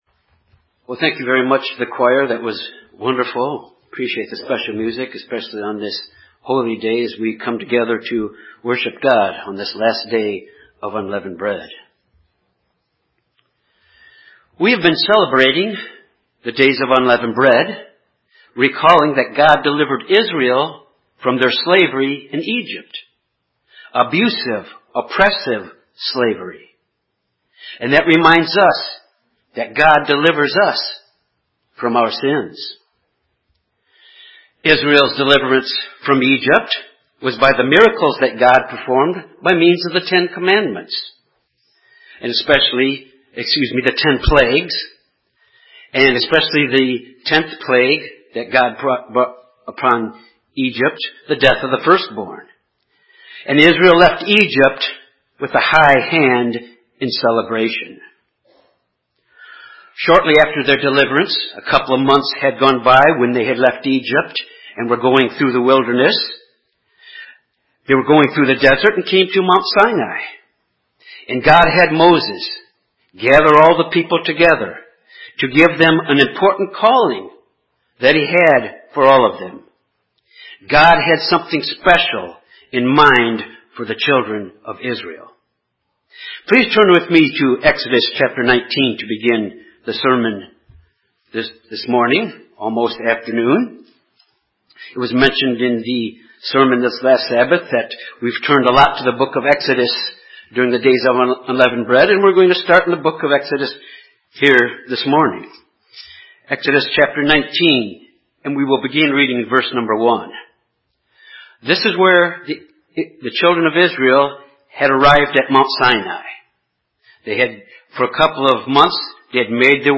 This sermon examines the character qualities to make our calling and elections sure so that we will never stumble.